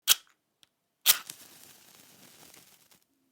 Match Strike and Light
SFX
yt_L7iBO9ZkoFA_match_strike_and_light.mp3